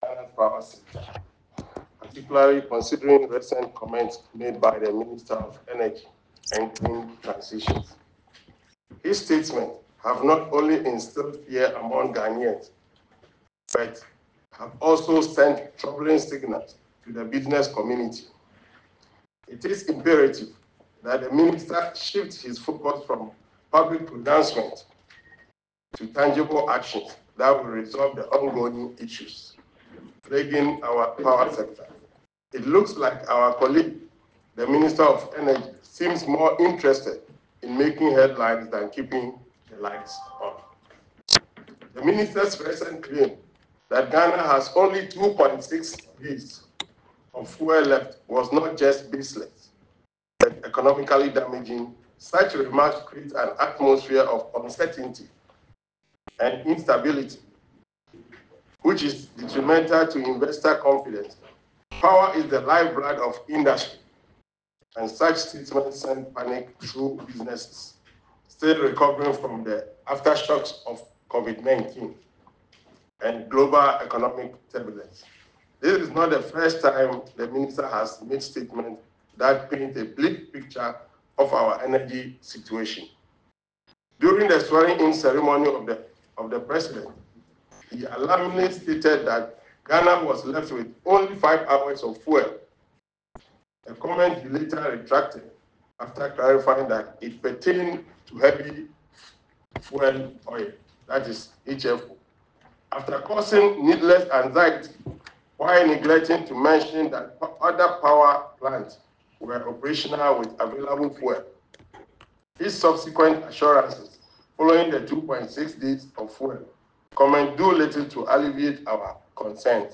Addressing journalists in Parliament on Monday, 19th May, Ranking Member on the Energy Committee, George Kwame Aboagye, voiced serious concern over the Minister’s approach.